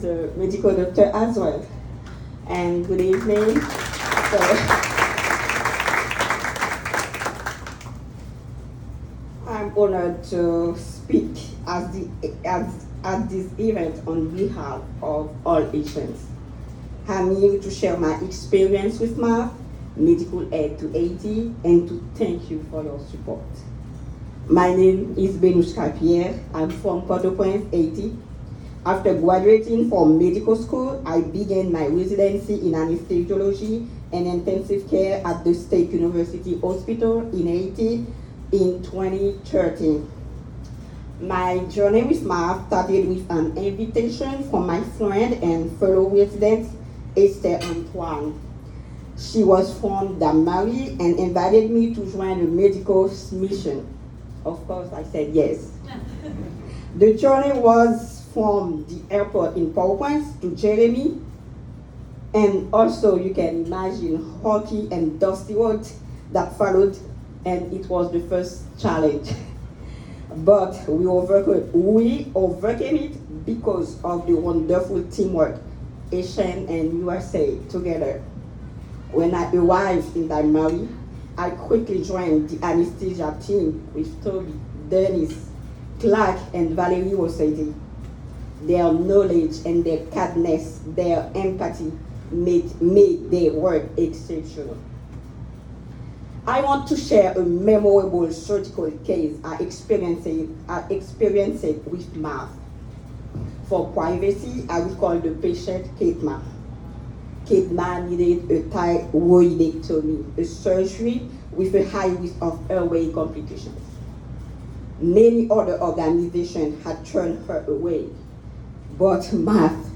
2025 Autumn's Palette